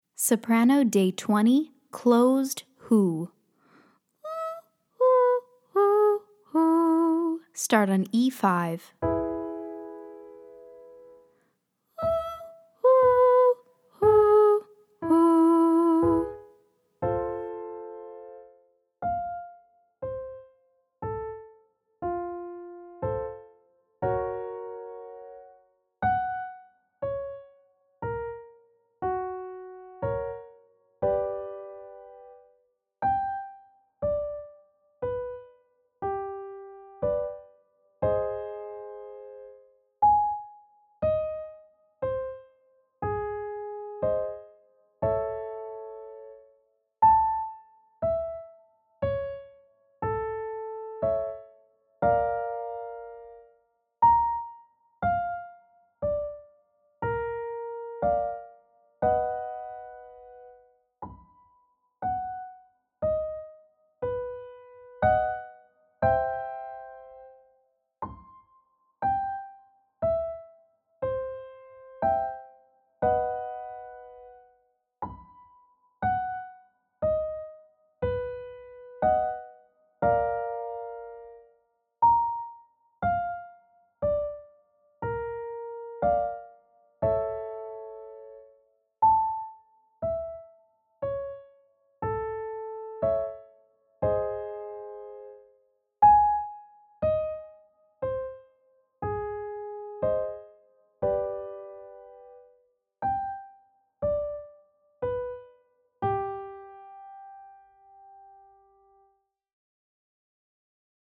Day 20 - Alto - Hooty 'HOO'